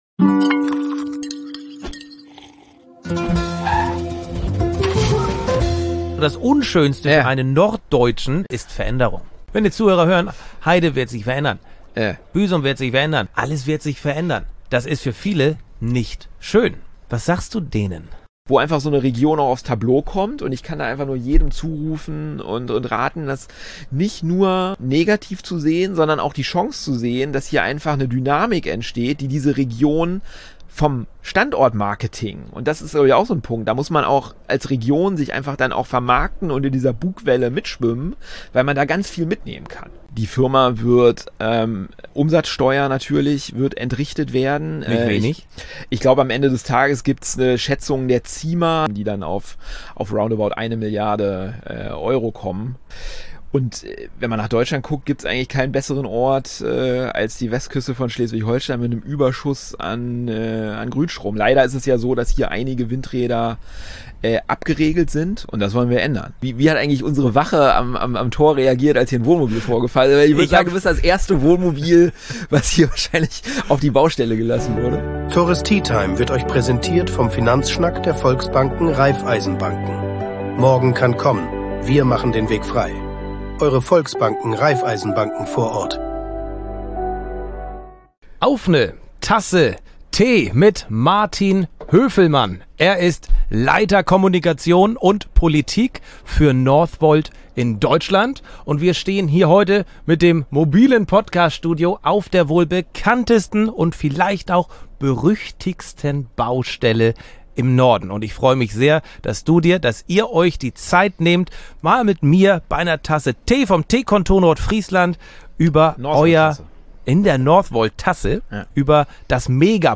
Ein spannender Austausch auf der Northvolt-Baustelle in meinem Podcaststudio auf vier Rädern.